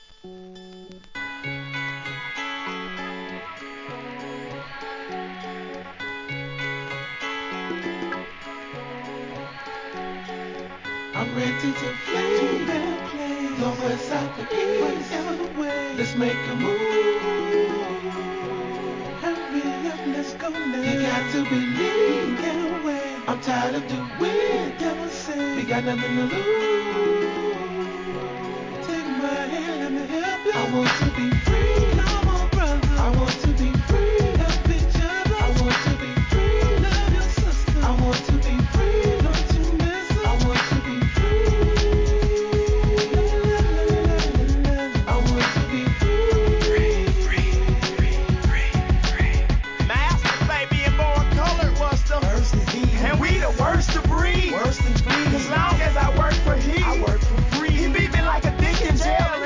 HIP HOP/R&B
コーラスを交えるメロ〜ナンバーも聴き所!!